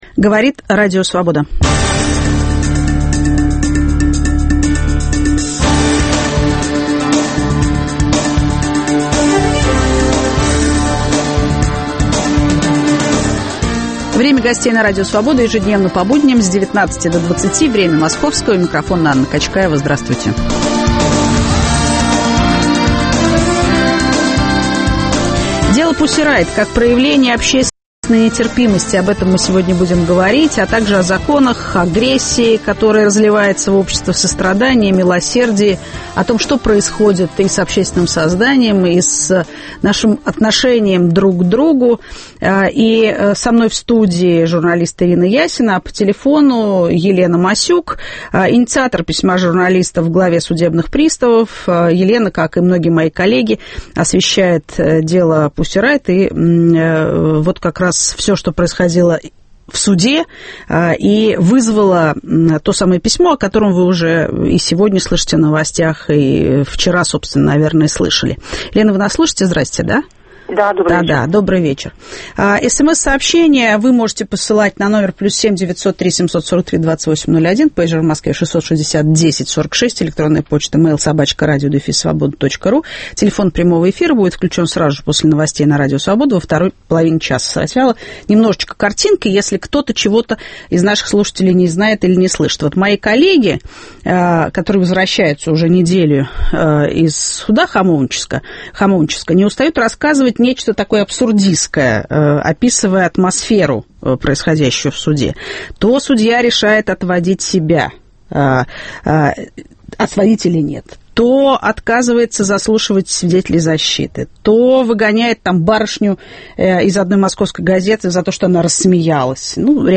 О законах, агрессии, сострадании и милосердии говорим с журналистами Ириной Ясиной и Еленой Масюк.